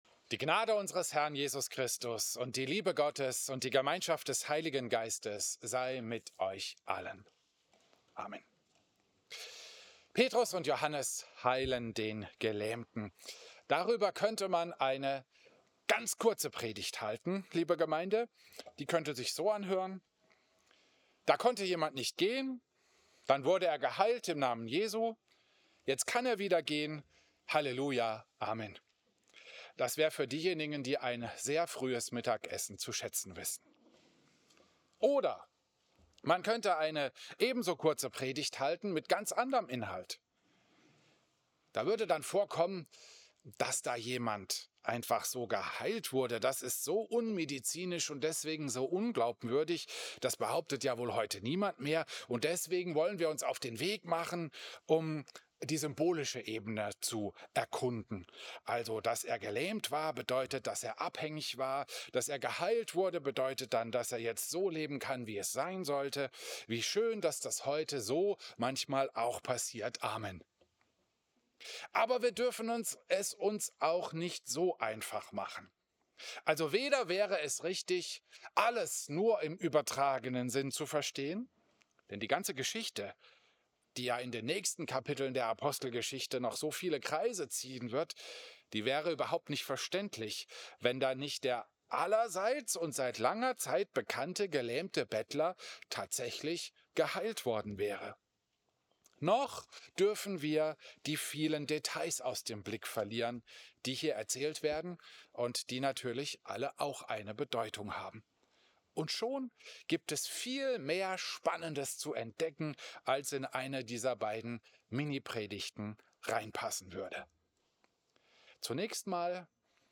Christus-Pavillon Volkenroda, 7.
Predigten